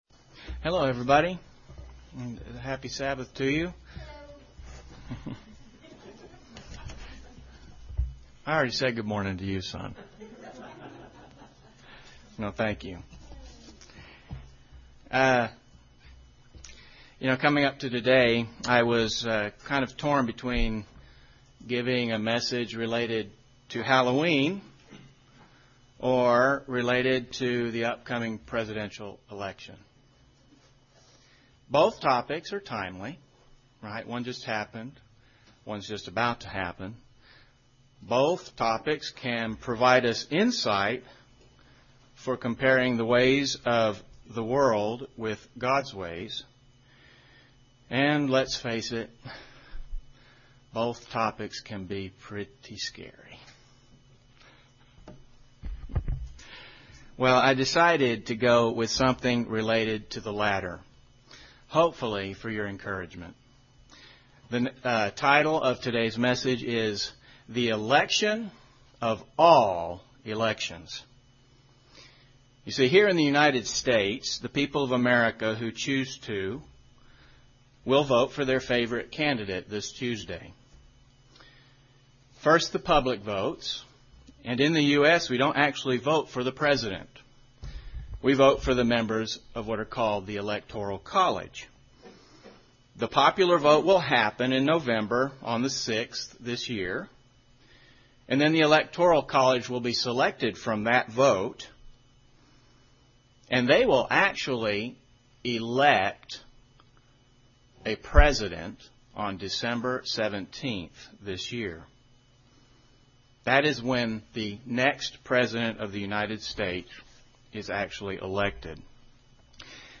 UCG Sermon chosen elect Stir up love and good works Notes Presenter's Notes Coming up to today, I was torn between giving a message related to Halloween or related to the upcoming presidential election.